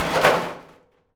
metal_sheet_impacts_12.wav